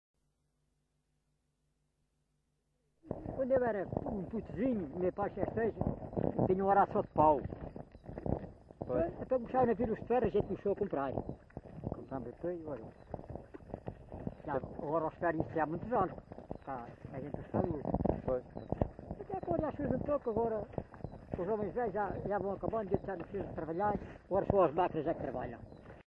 LocalidadeMonsanto (Idanha-a-Nova, Castelo Branco)